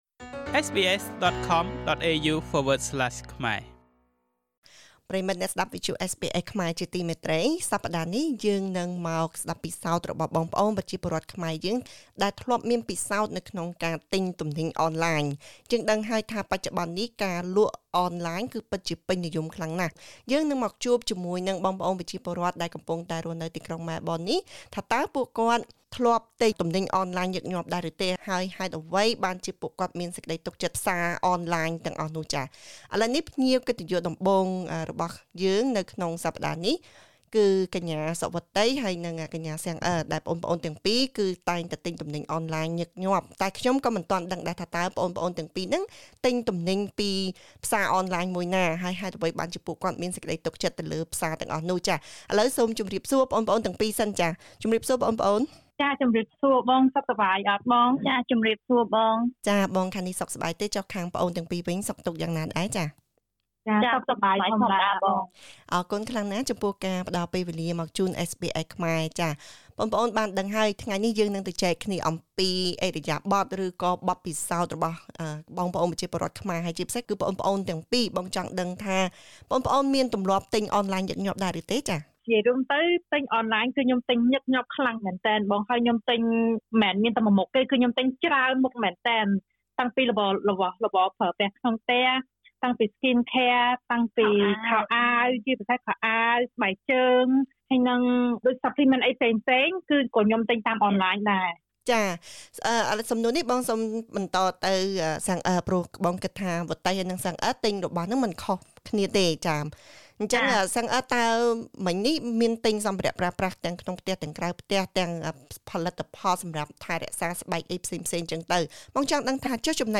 ទីផ្សារអនឡាញកំពុងពេញនិយមក្នុងចំណោមមនុស្សគ្រប់វ័យ ជាពិសេសក្នុងស្រទាប់យុវជន ទោះបីជាគេដឹងថា វាប្រឈមនឹងហានិភ័យនៃការបោកប្រាស់ និងលួចអត្តសញ្ញាណក៏ដោយ។ តើទំនិញអ្វីដែលពេញនិយម? តើផ្សារអនឡាញមួយណាដែលយុវជនកំពុងចូលចិត្ត? សូមស្តាប់បទសម្ភាសន៍ជាមួយយុវជនខ្មែរអូស្រ្តាលី។